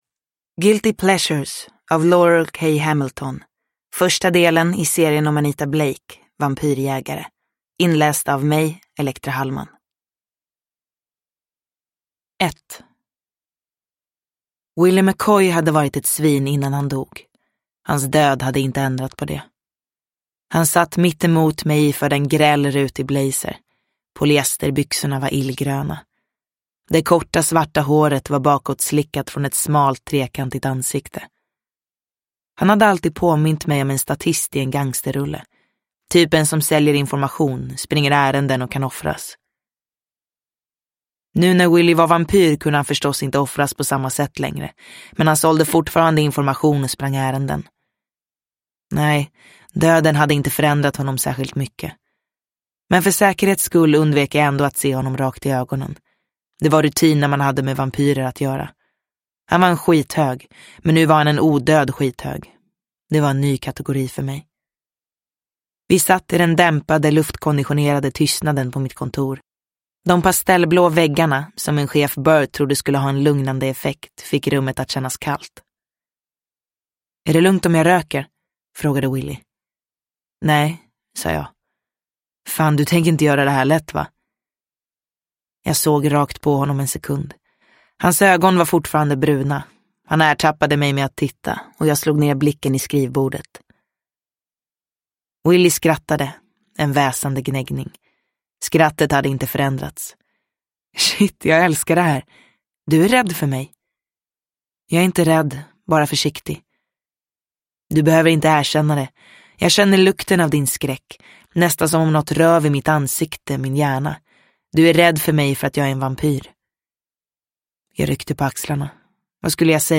Guilty pleasures – Ljudbok – Laddas ner